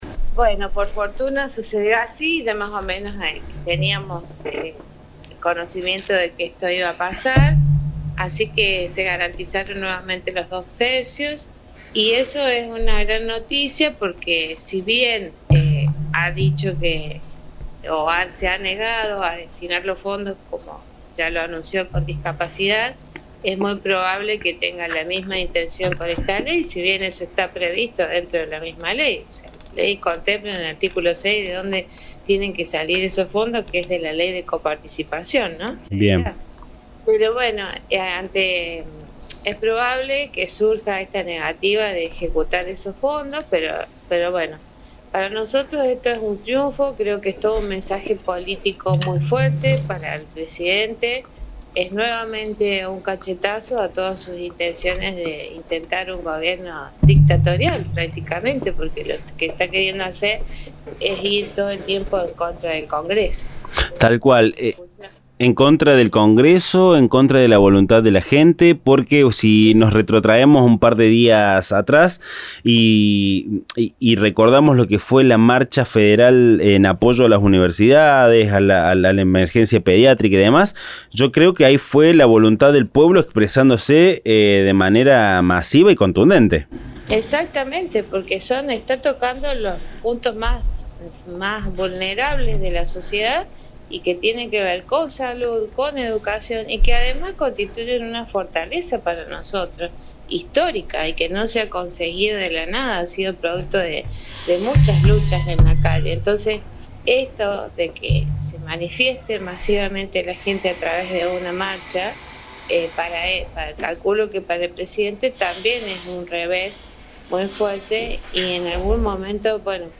En Acento Mercedino por Radio UNSL Villa Mercedes dialogamos